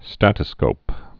(stătə-skōp)